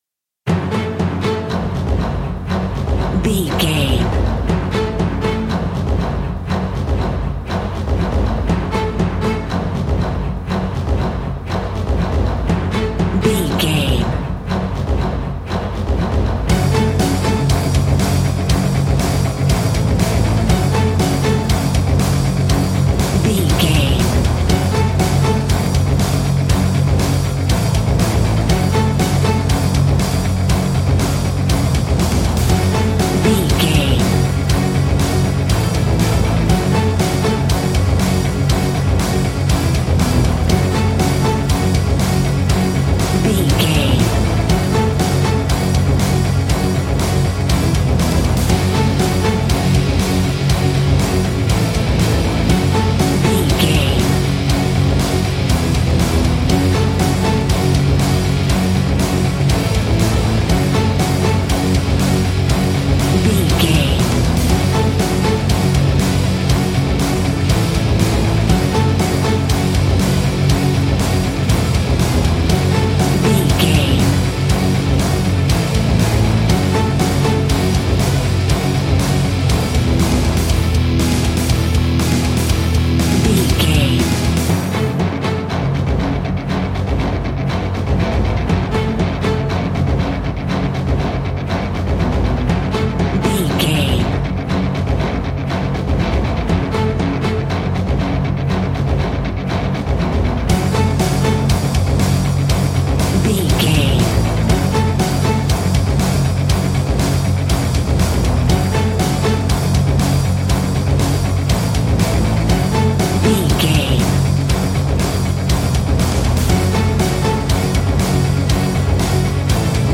Epic / Action
Fast paced
Aeolian/Minor
F#
hard rock
heavy metal
horror rock
instrumentals
Heavy Metal Guitars
Metal Drums
Heavy Bass Guitars